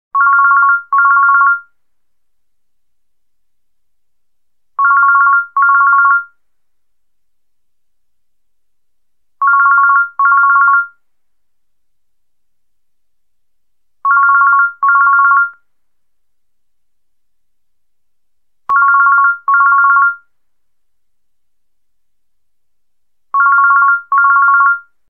Category: Old Phone Ringtones